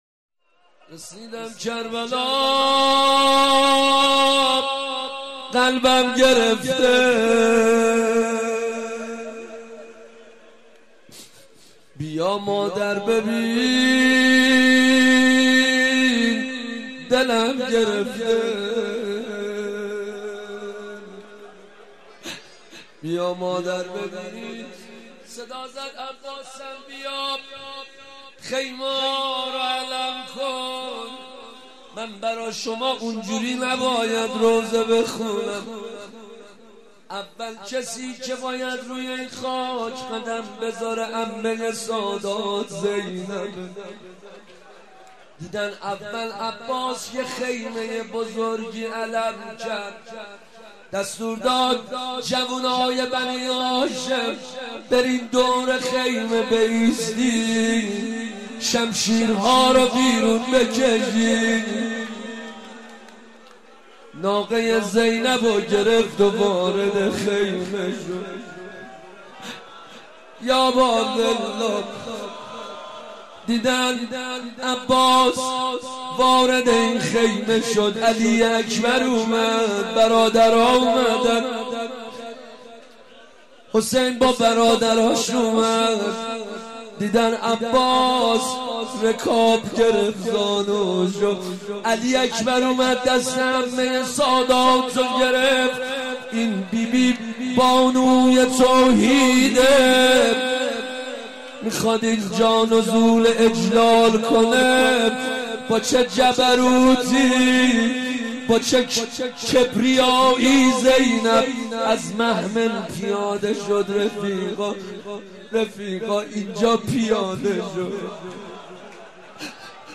روضه شب دوم